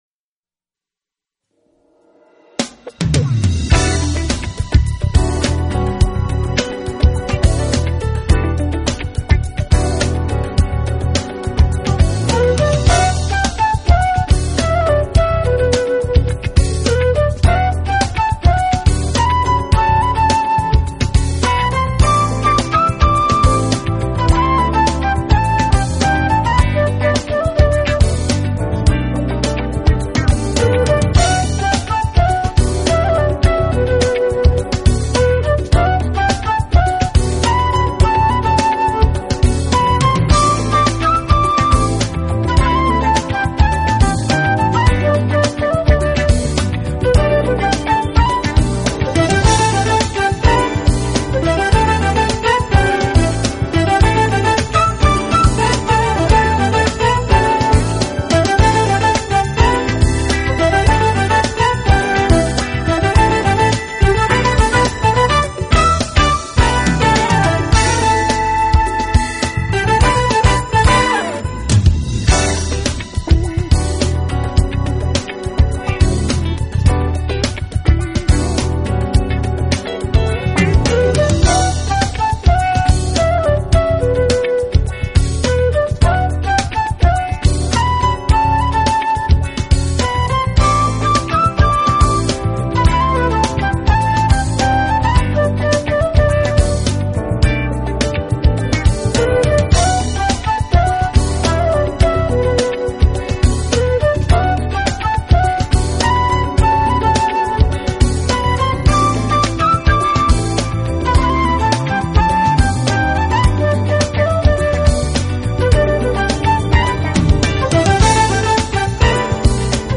Smooth Jazz